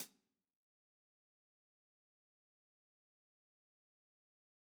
ClosedHH Zion 2.wav